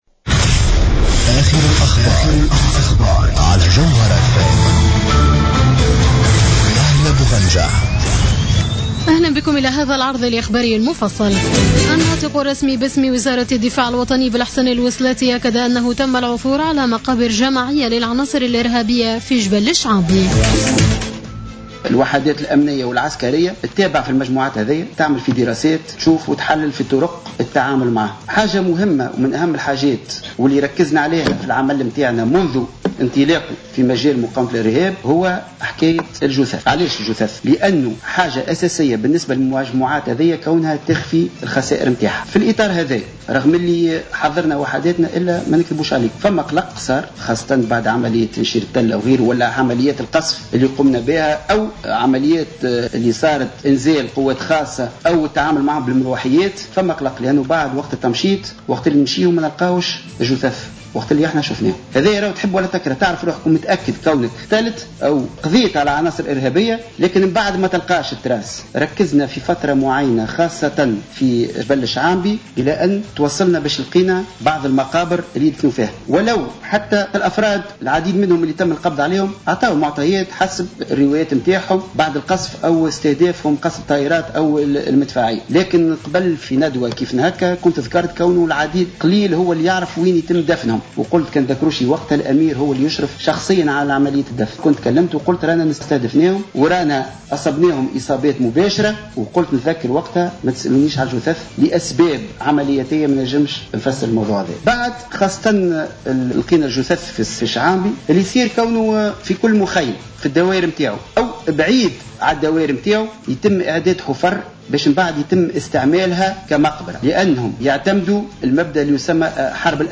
نشرة أخبار السابعة مساء ليوم السبت 09 ماي 2015